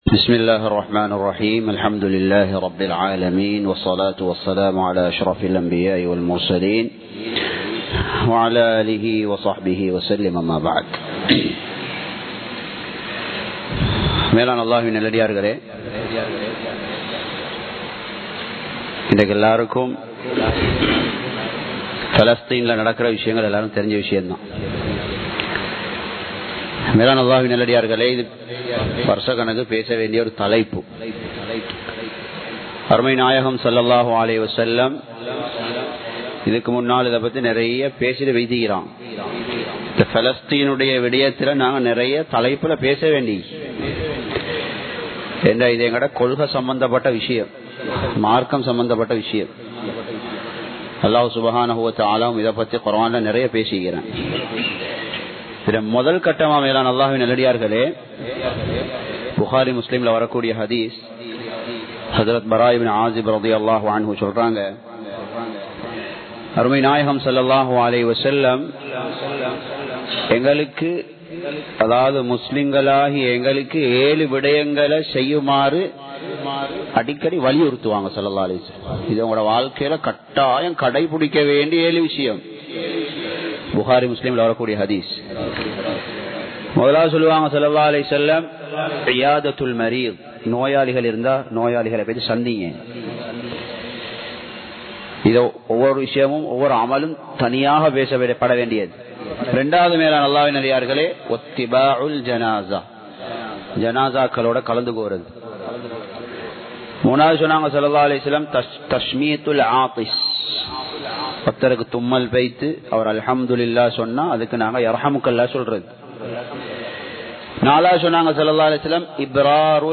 அநியாயம் செய்யப்படுபவர்களுக்கு உதவுங்கள் | Audio Bayans | All Ceylon Muslim Youth Community | Addalaichenai
Muhiyadeen Jumua Masjith